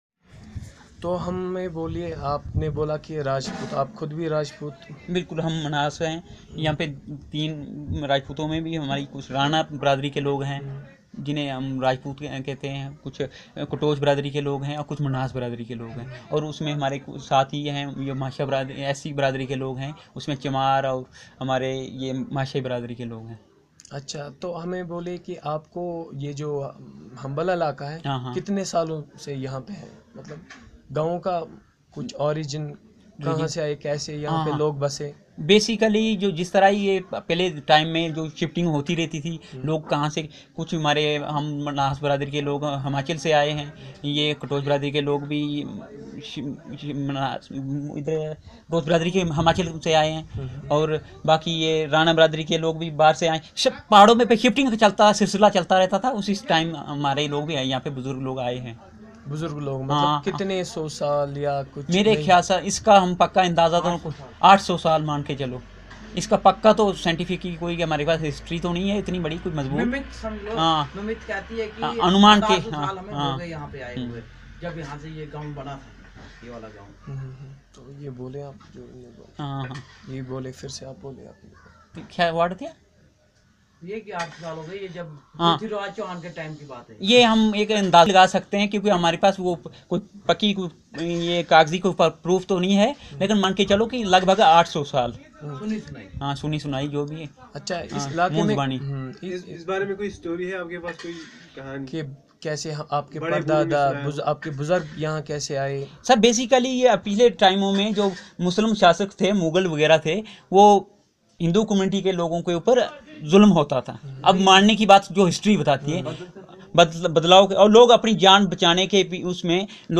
Narrative about the communities, religion , education and occupation of the natives